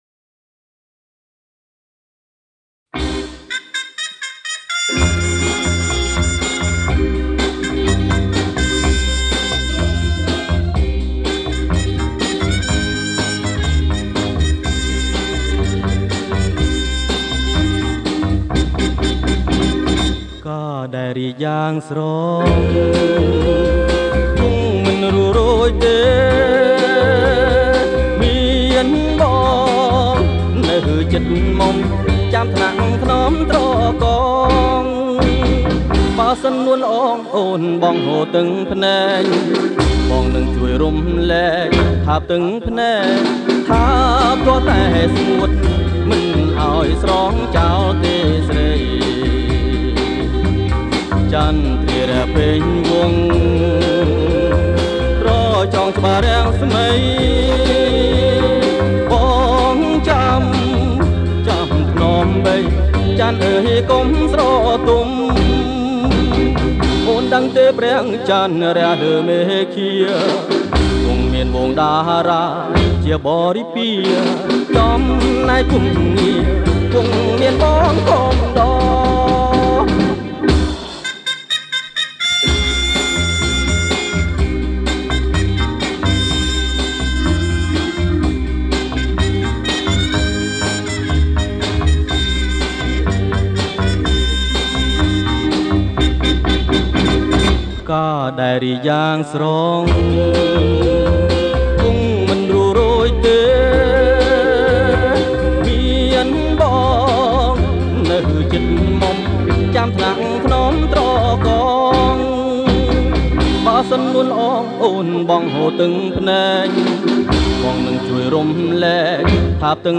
ប្រគំជាចង្វាក់ Bolero Jerk